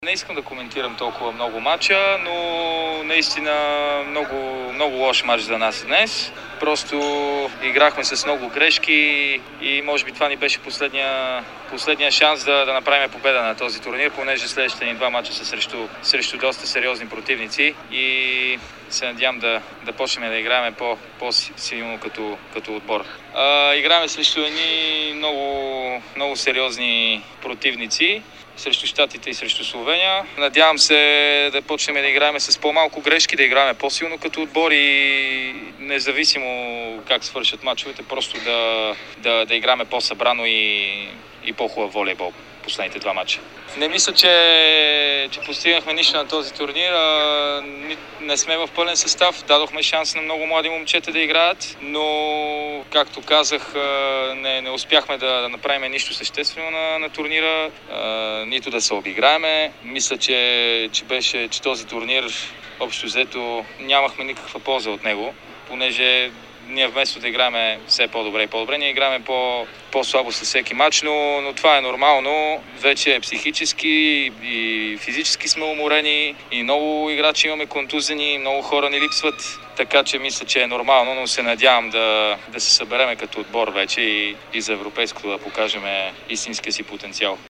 Посрещачът Мартин Атанасов бе откровен при отговорите на въпросите на Дарик и dsport във виртуалната миксзона в Римини като обяви, че според него мъжкият ни национален отбор по волейбол е нямал никаква полза от това си участие в Лигата на нациите, че играчите вместо да заиграят по-добре, го правят по-зле и не пропусна надеждата си вече за Европейското България да се събере и да заиграе като отбор.